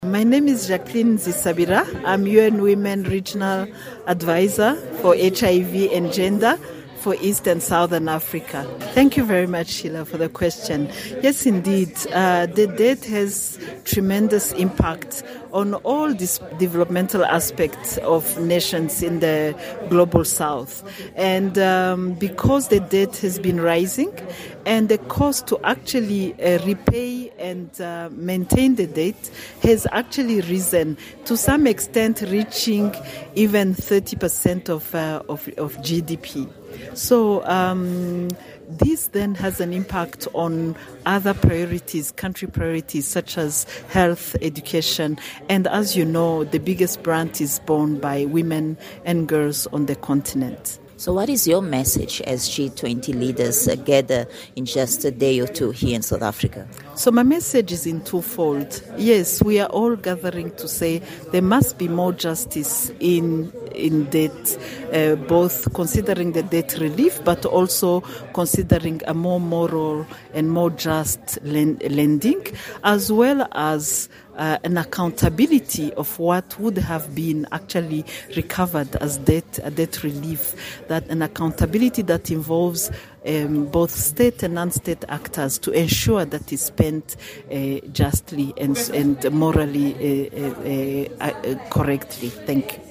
At the conclusion of the G20 Social Summit side event on Wednesday, 18 November